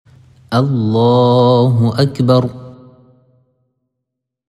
Cliquez ci-dessous pour écouter la prononciation de la parole الله أكبر « Allahou ‘akbar »
Takbir.mp3